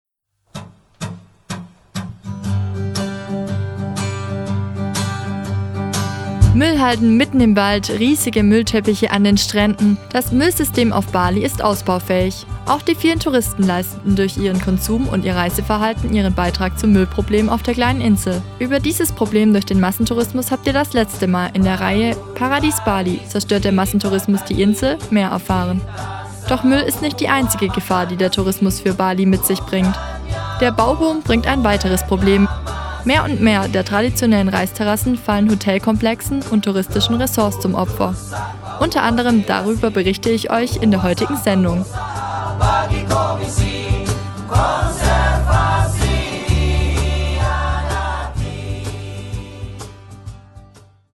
Paradies Bali: Zerstört der Massentourismus die Insel? Feature, Teil 3 (487)